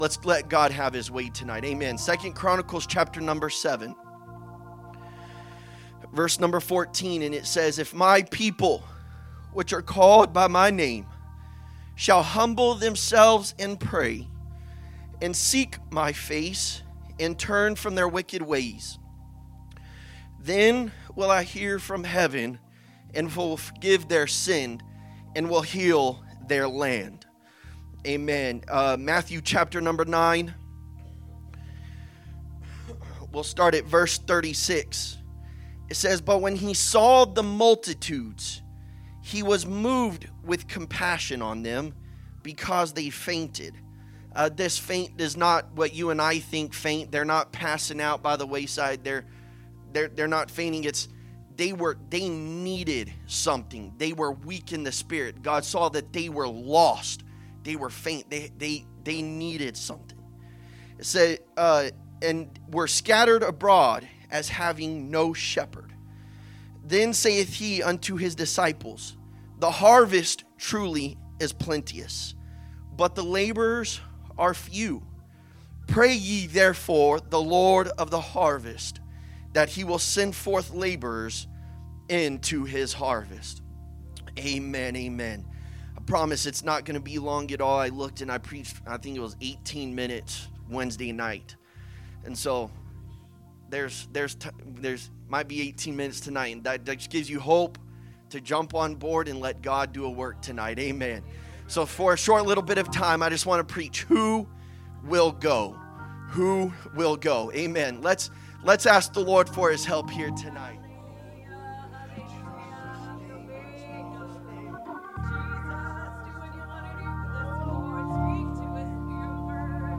A message from the series "Guest Speakers." 6/22/2025 Sunday Evening Service